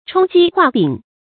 充饥画饼 chōng jī huà bǐng
充饥画饼发音